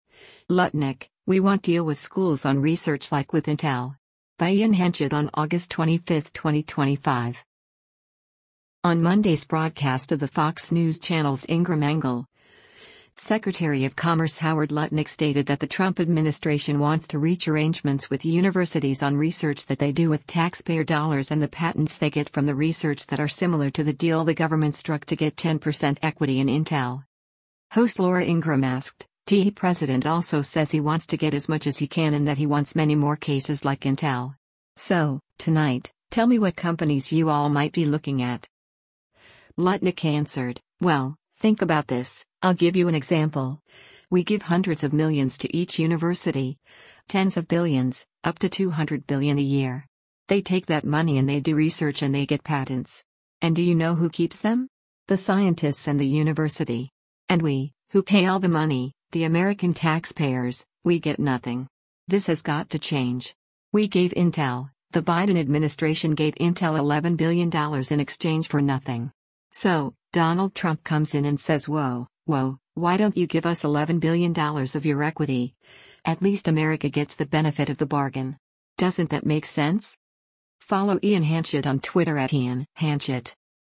On Monday’s broadcast of the Fox News Channel’s “Ingraham Angle,” Secretary of Commerce Howard Lutnick stated that the Trump administration wants to reach arrangements with universities on research that they do with taxpayer dollars and the patents they get from the research that are similar to the deal the government struck to get 10% equity in Intel.